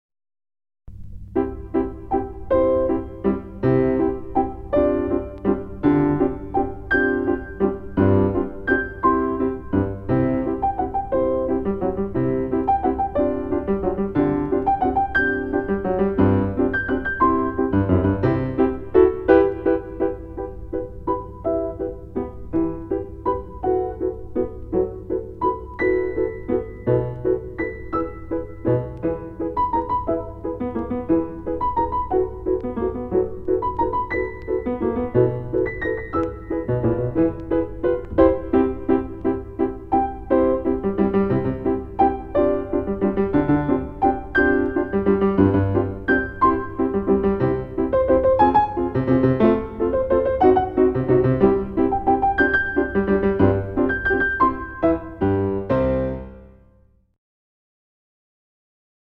piano-Música-1-baobab-12-Qui-té-raó2.mp3